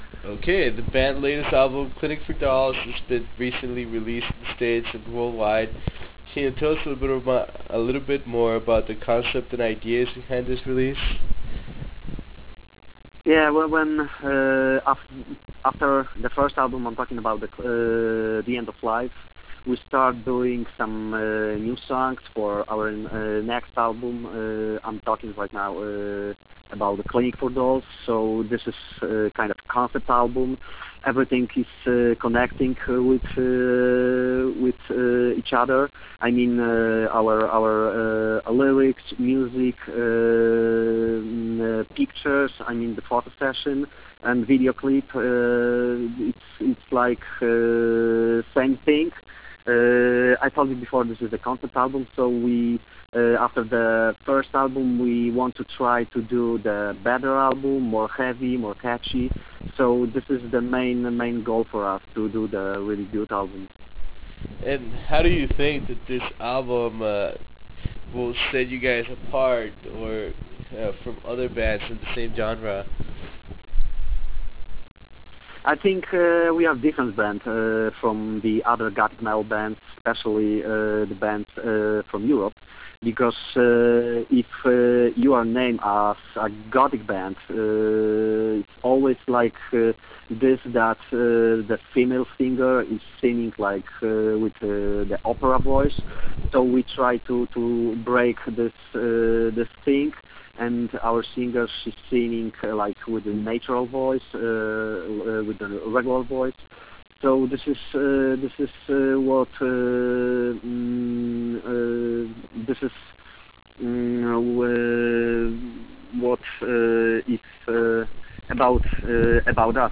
Interview with Unsun